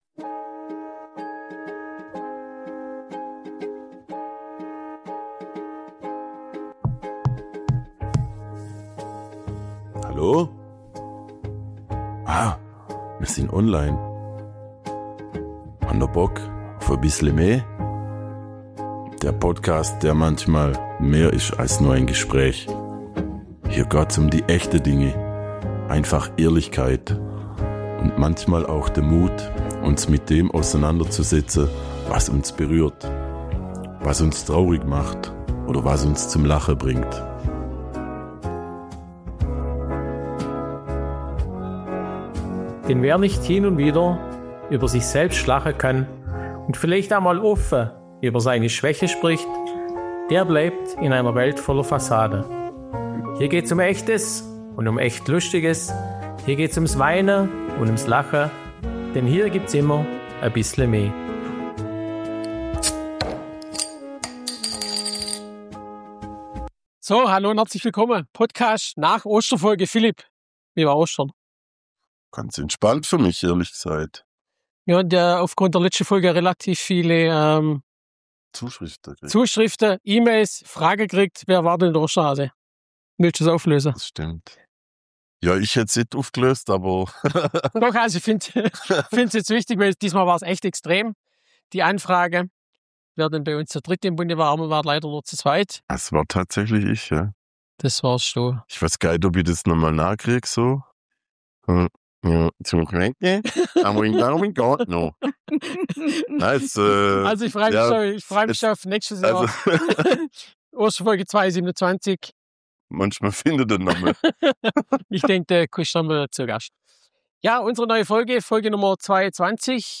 #22 Des hät a Gschmäckle ~ Bissle me – Schwoba-Podcast aus´m Schlofsack Podcast